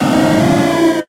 Grito de Noctowl.ogg
Grito_de_Noctowl.ogg.mp3